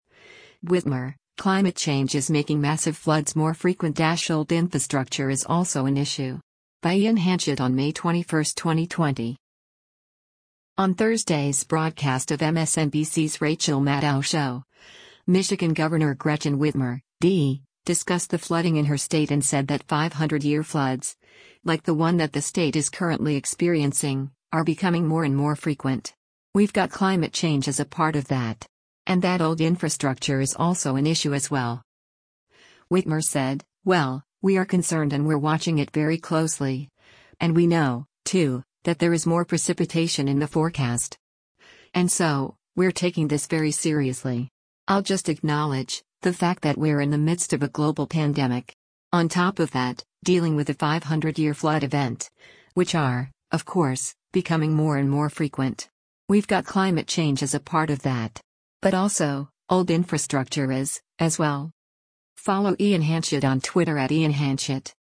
On Thursday’s broadcast of MSNBC’s “Rachel Maddow Show,” Michigan Governor Gretchen Whitmer (D) discussed the flooding in her state and said that 500-year floods, like the one that the state is currently experiencing, are “becoming more and more frequent. We’ve got climate change as a part of that.” And that “old infrastructure” is also an issue as well.